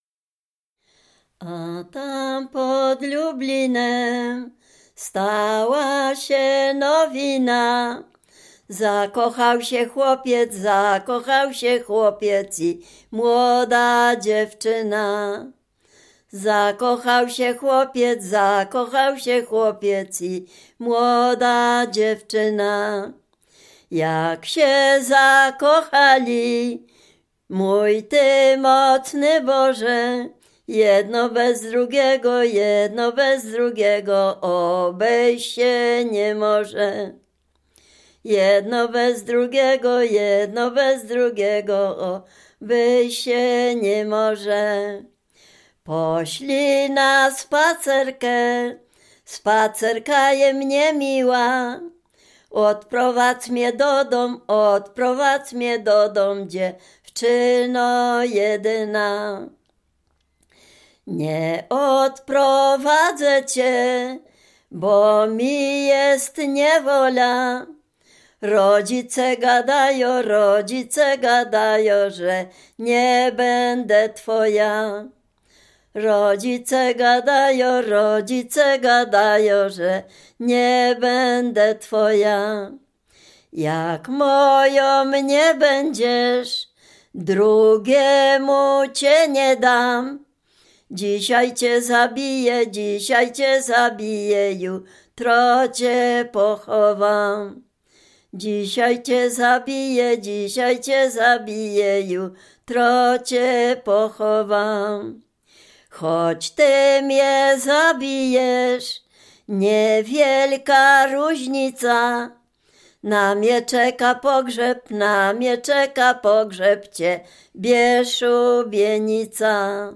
liryczna miłosna